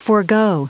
Prononciation du mot forgo en anglais (fichier audio)
Prononciation du mot : forgo